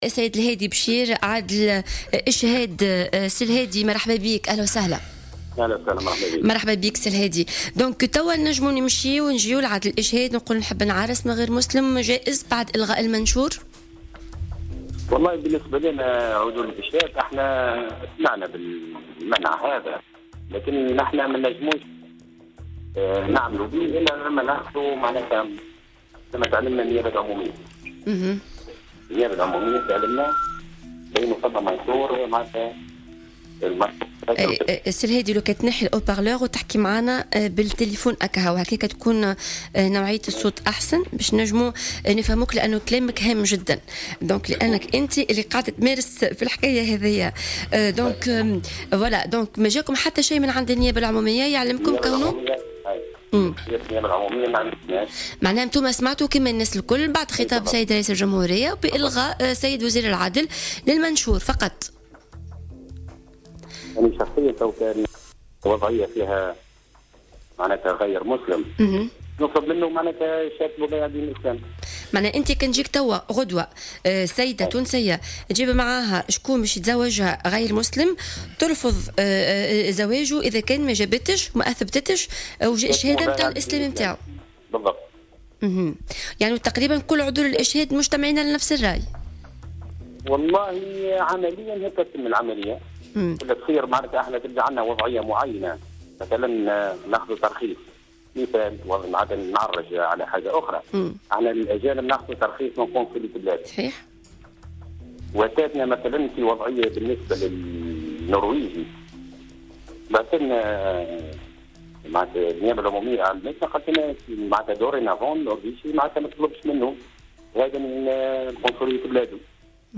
خلال مداخلته في برنامج "S.O.S Avocat" على موجات الجوهرة اف ام